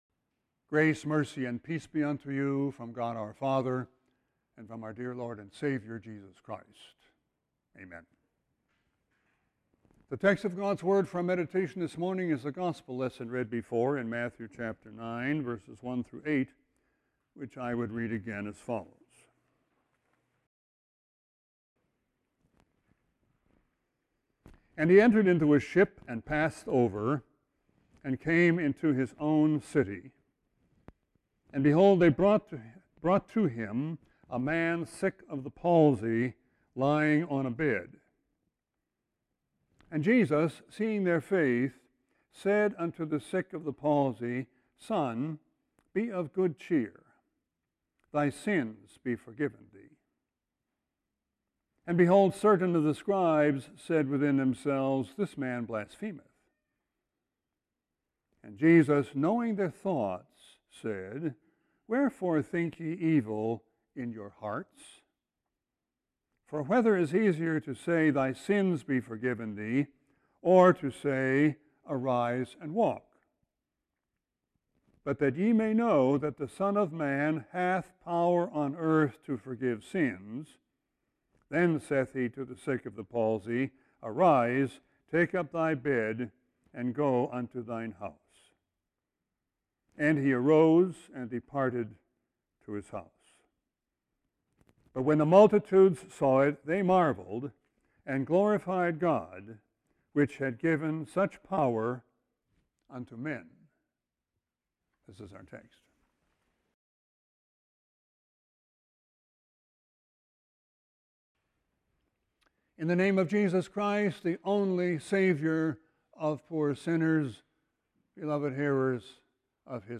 Text: Matthew 9:1-8 This text will be replaced by the JW Player Right click & select 'Save link as...' to download entire Sermon video Right click & select 'Save link as...' to download entire Sermon audio